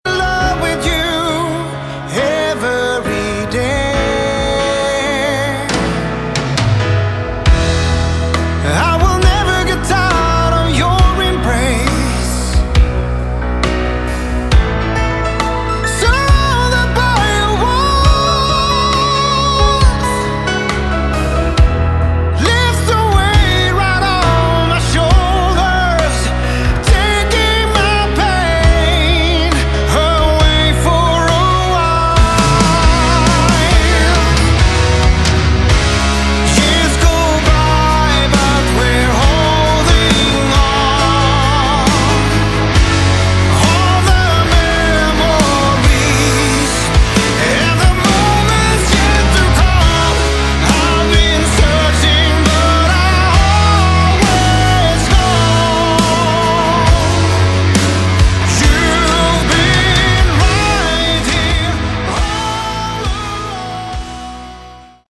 Category: Melodic Rock
vocals
guitar
keyboards
bass guitar
drums